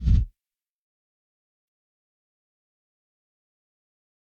flop4.ogg